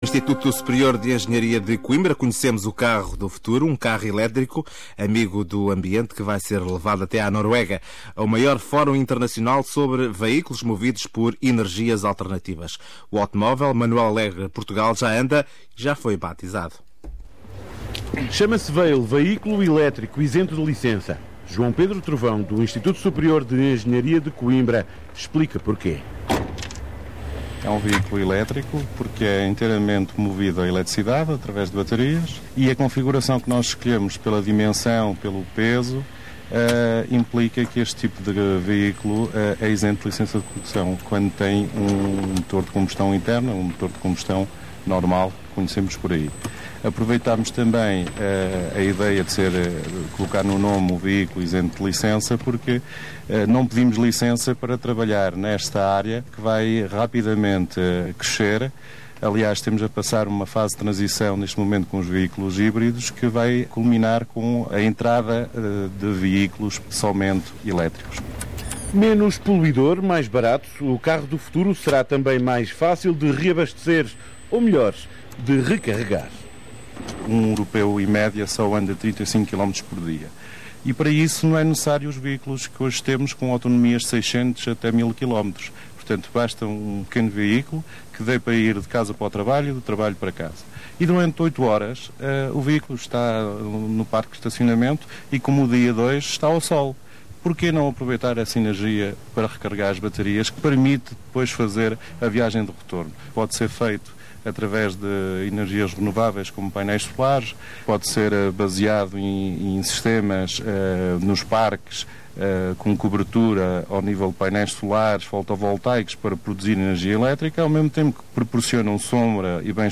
Noticia / Entrevista